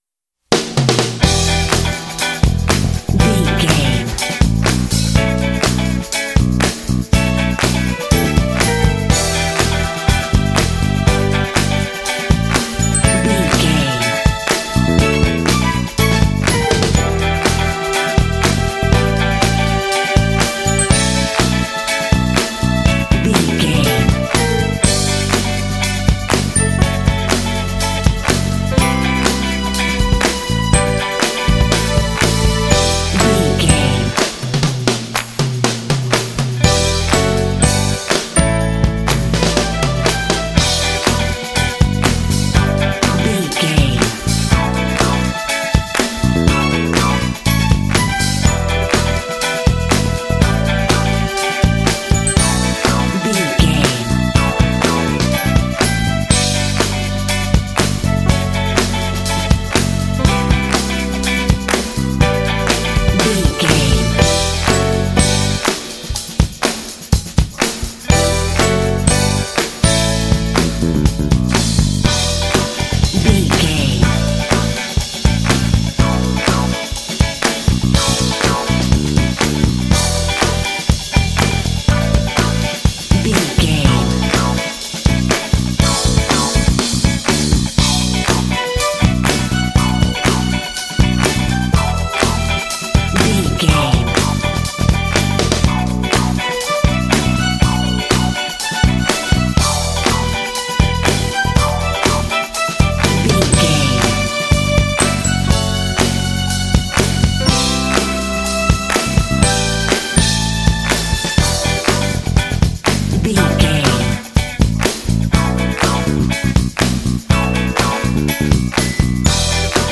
Aeolian/Minor
funky
groovy
driving
energetic
lively
piano
bass guitar
electric guitar
drums
strings